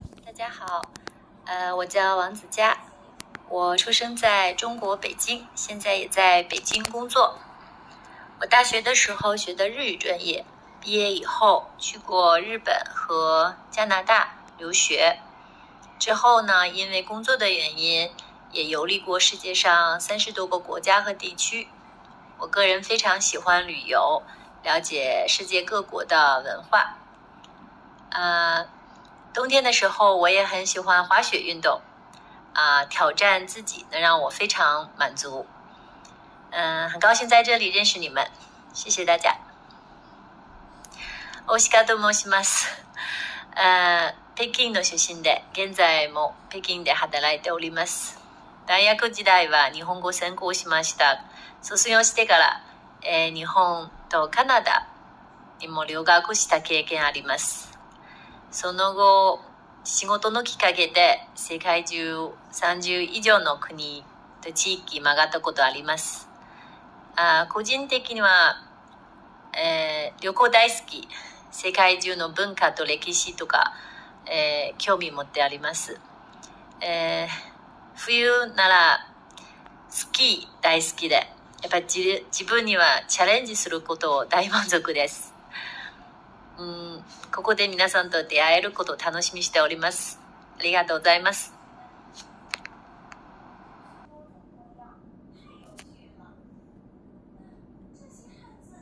自己紹介：